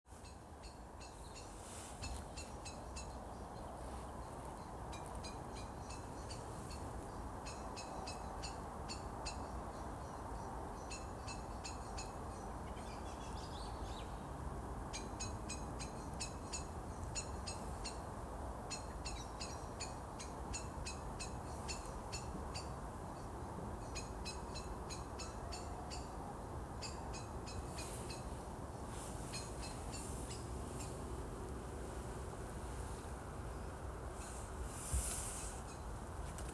Another day on the other side, as I am just among the deep red heads of the swamp cypresses I hear a strange call – a single repeated note – a note with an odd slight echo. I get out my phone and make a recording, checking it on the birdcall app – I repeat the recording – once – twice – three times – each time getting the same result …Greater Spotted Woodpecker it reads.